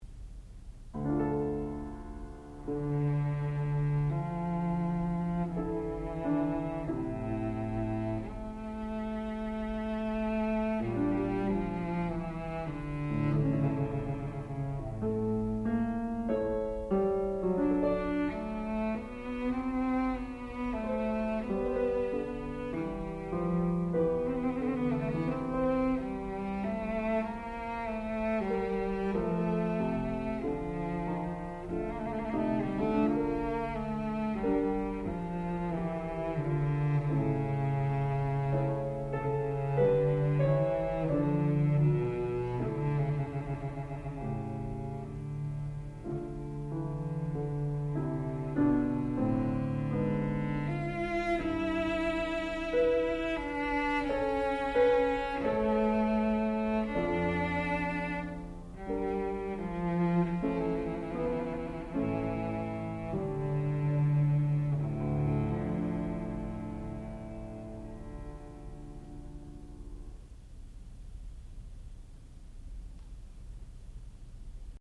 sound recording-musical
classical music
violoncello
piano